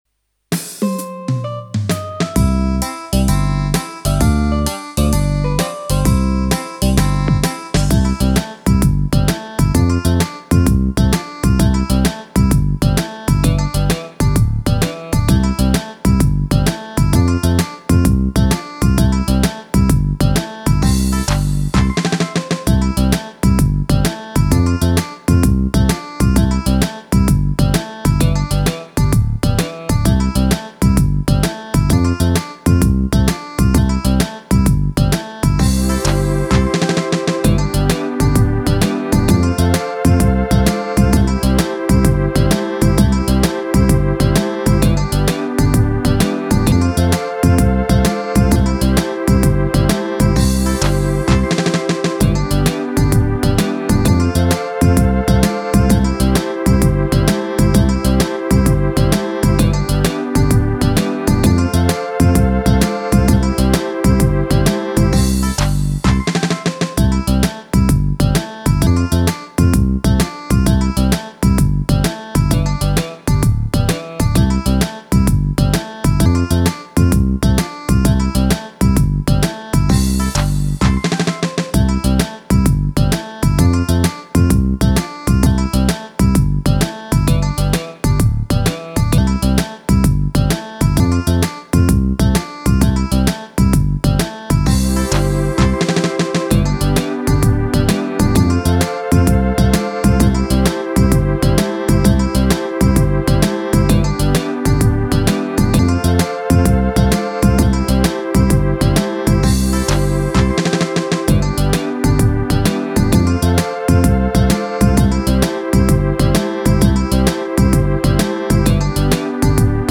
Country
Het is een ritme met een uitgesproken, speels karakter dat meteen een heel eigen sfeer neerzet.
Akkoorden Progressie
Roland E X 10 Country 128 Country 1 Chord Progression Style Mp 3